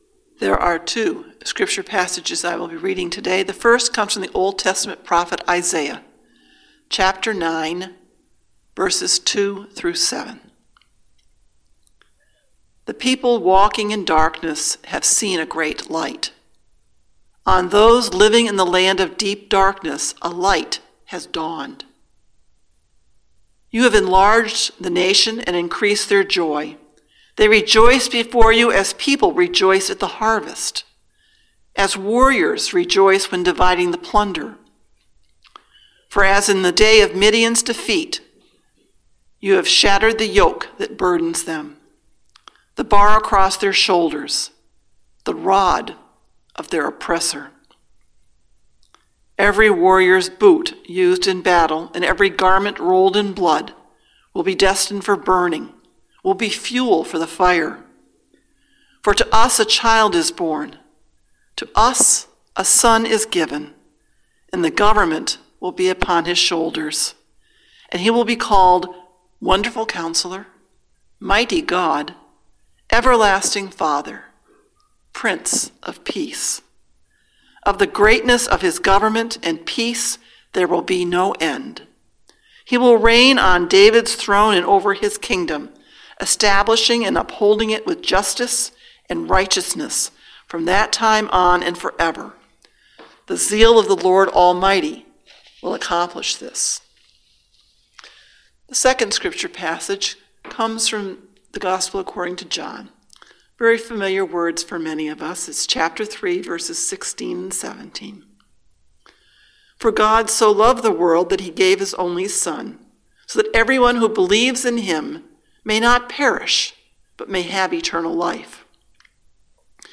Sermon: Turning Christmas Around | First Baptist Church, Malden, Massachusetts